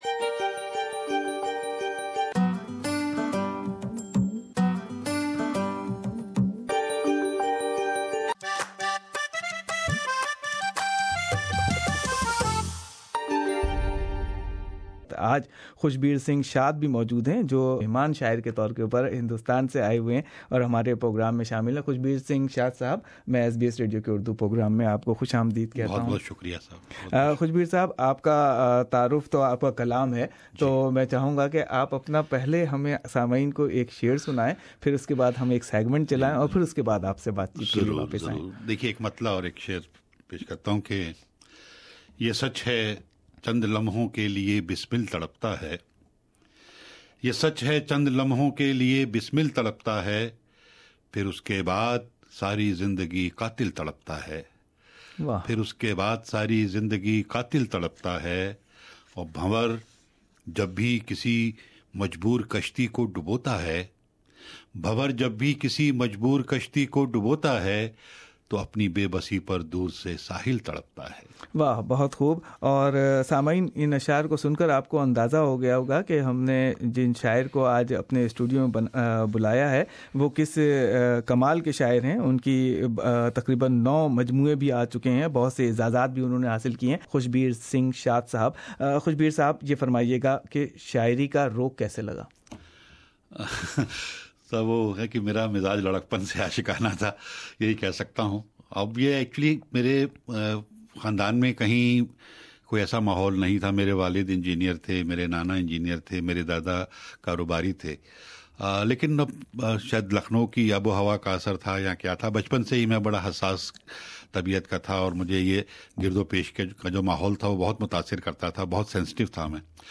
Listen visiting poet talk and poetry Kalam e Shaer - Bazaban e Shaer.